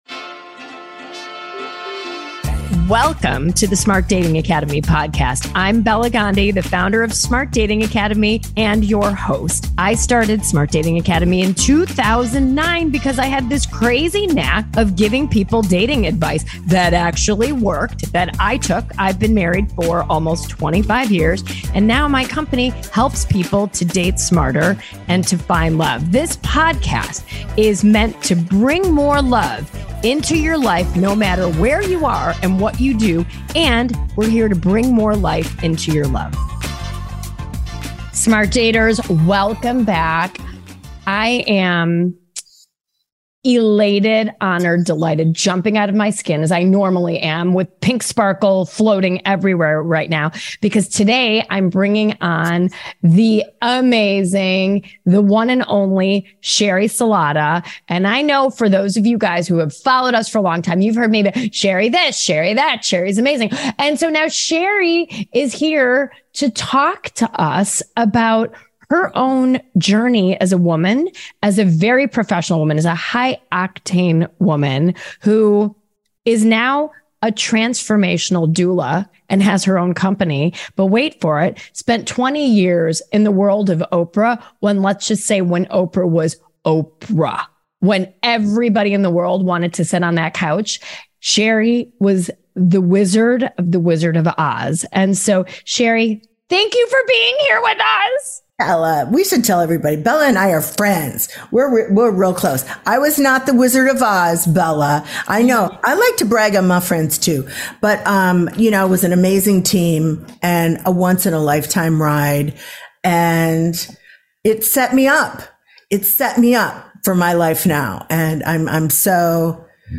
Listen to this chat with Sheri Salata, the woman who was Oprah's right hand Executive Producer when Oprah ruled the planet! Sheri wrote a book called "The Beautiful No", and we chat about her life at Oprah, and when she decided to pursue her OWN life at 56 years old. She will tell you HOW she did it, and has the BEST life she could imagine!